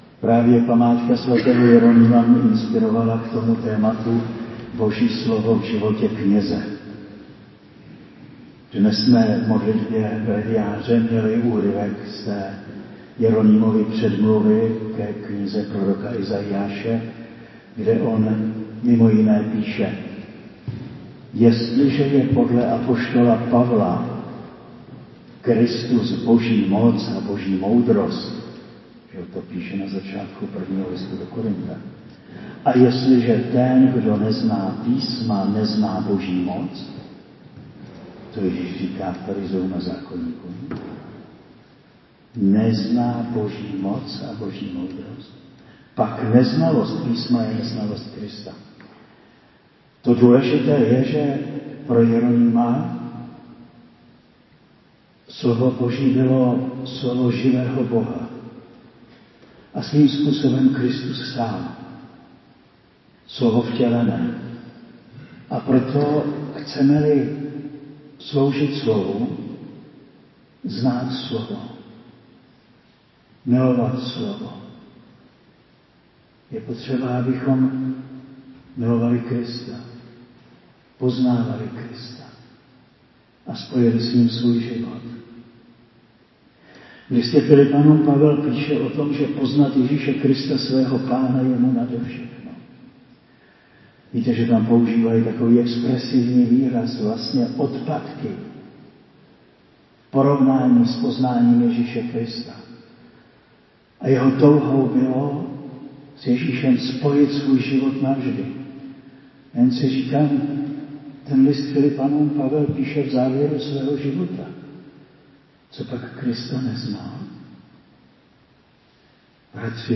Do shromážděného společenství duchovních dorazil s přednáškou na téma Boží slovo v životě kněze.